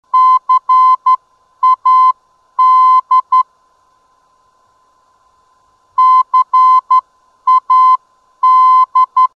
CAD - Cadillac heard on 269 kHz: (65kb)